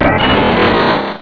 Cri de Jungko dans Pokémon Rubis et Saphir.